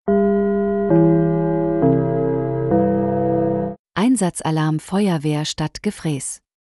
Alarmierung
Gong-Einsatzalarm-Feuerwehr-Stadt-Gefrees.mp3